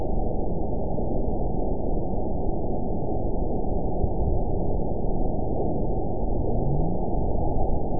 event 912186 date 03/20/22 time 07:53:37 GMT (3 years, 1 month ago) score 9.28 location TSS-AB02 detected by nrw target species NRW annotations +NRW Spectrogram: Frequency (kHz) vs. Time (s) audio not available .wav